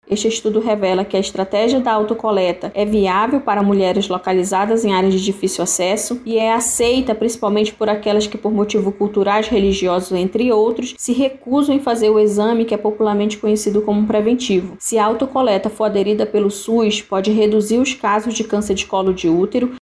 SONORA-2-PESQUISA-AUTOCOLETA-2.mp3